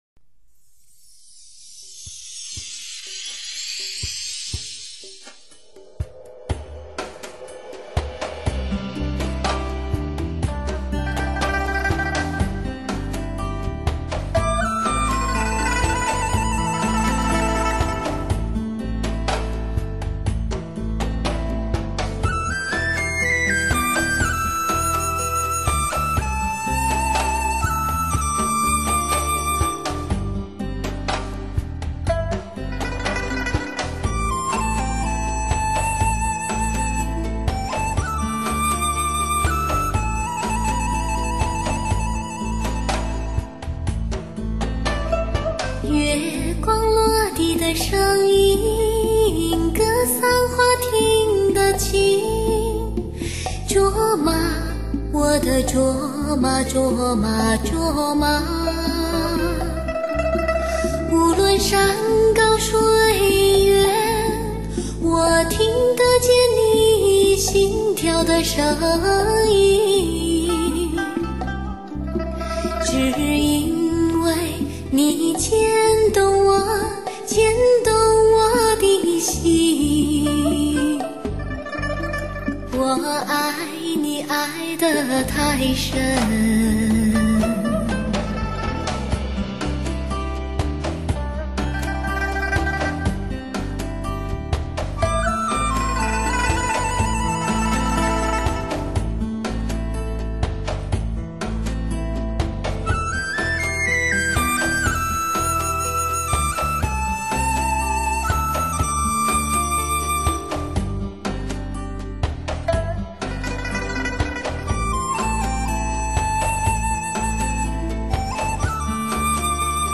二为一，既保留了黑胶LP唱盘的高保真与自然感，
又具备了CD的高清晰与低噪音的优点，实现普通CD